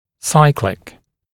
[‘saɪklɪk(l)] [‘сайклик(л)] циклический, цикличный (также cyclical)
cyclical.mp3